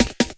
41 LOOPSD2-R.wav